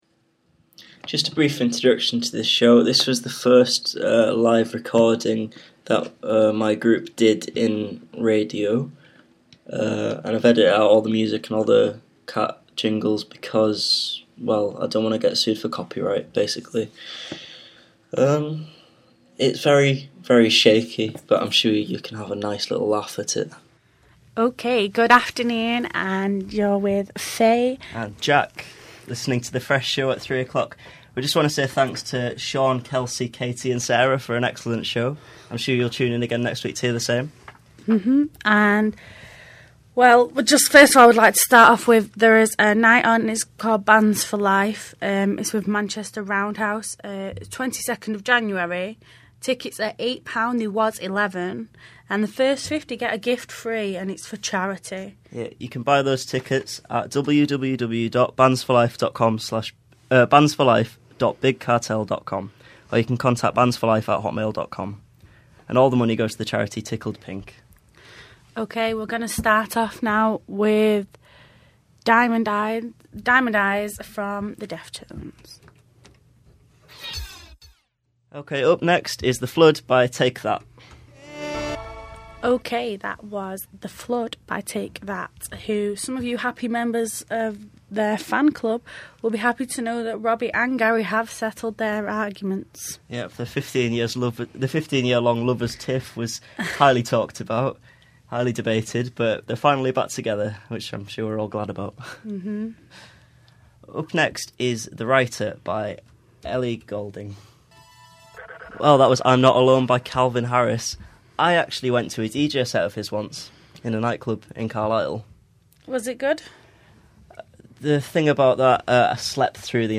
I edited out all the jingles and music for what I guess are copyright reasons (also I don't like either).